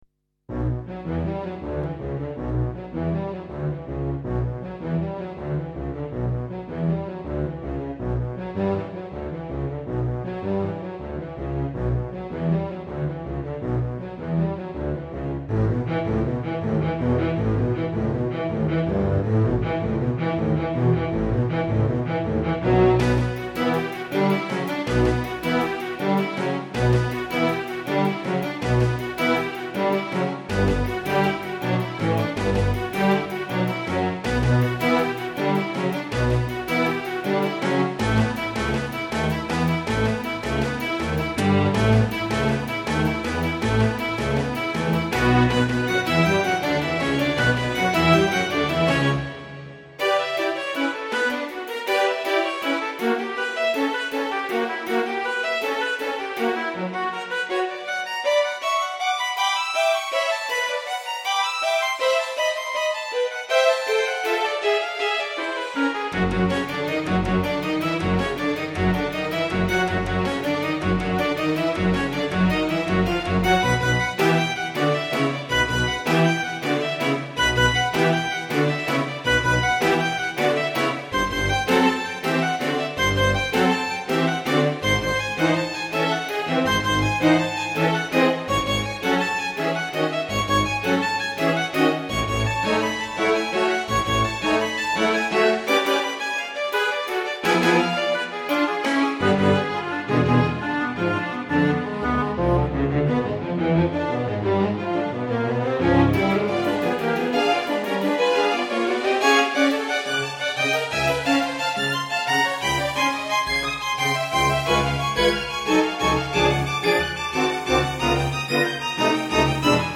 pour petit orchestre à cordes